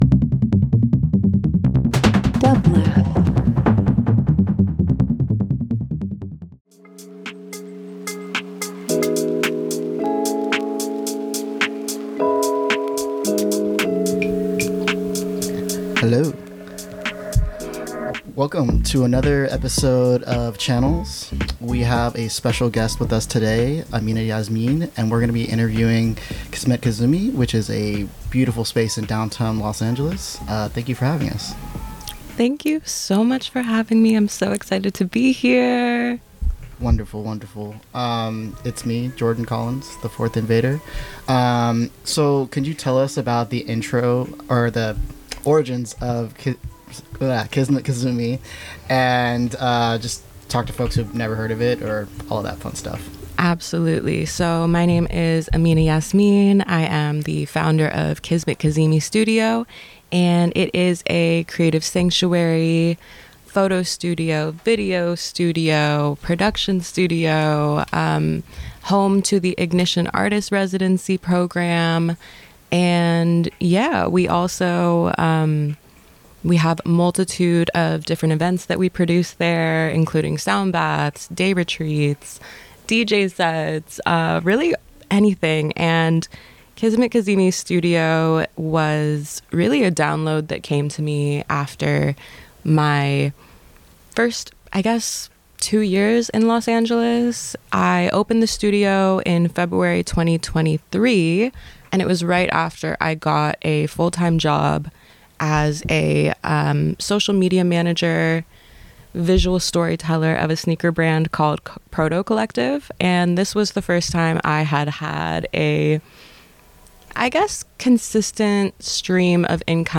The music is carefully selected to reflect the thoughts, feelings, and subjects that emerge during each conversation, creating a truly immersive and thought-provoking listening experience.